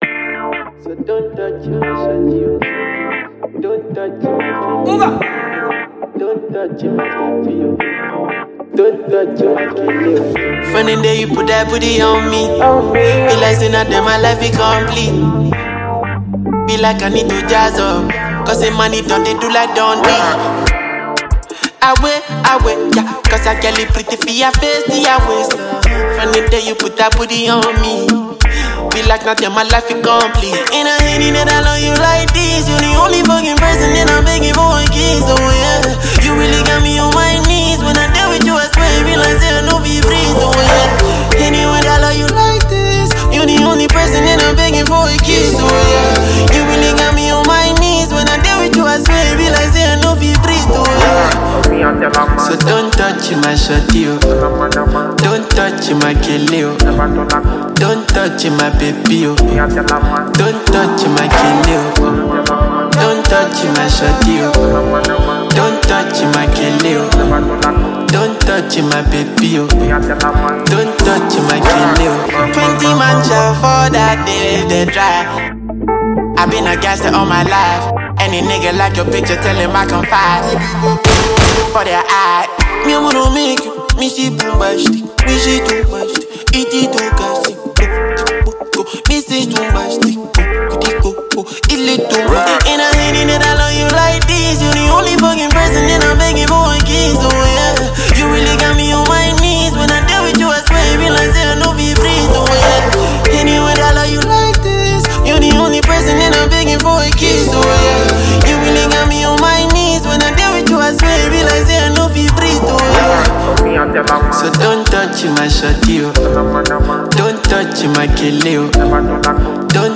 rapper and singer